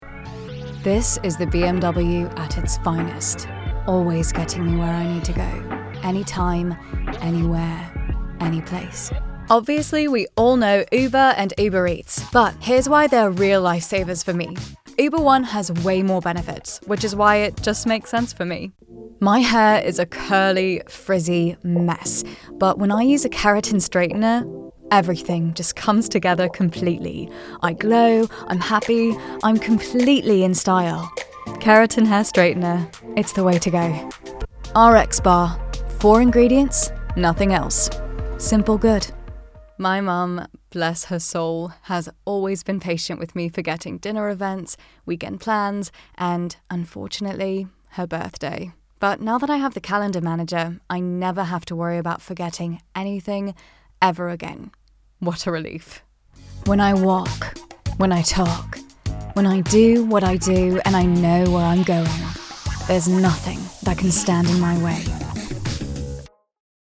British Young, Friendly Edgy Collection